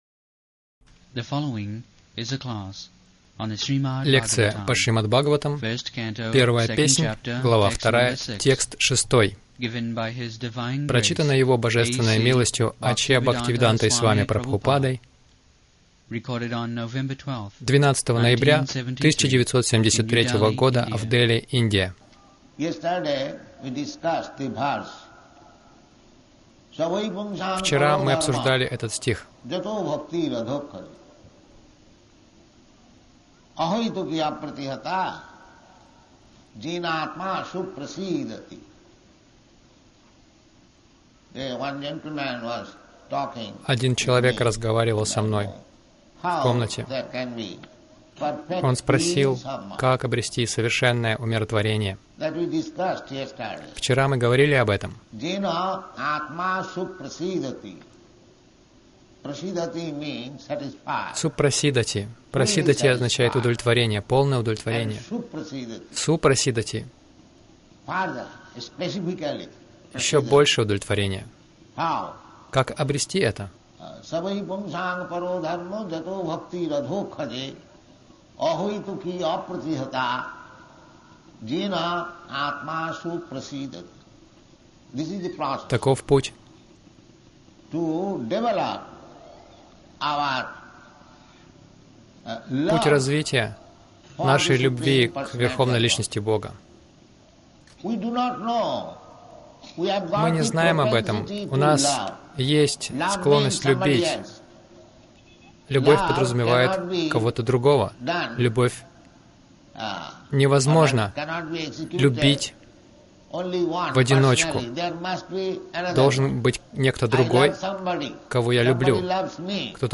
Милость Прабхупады Аудиолекции и книги 12.11.1973 Шримад Бхагаватам | Нью-Дели ШБ 01.02.06 — Как обрести полное умиротворение Загрузка...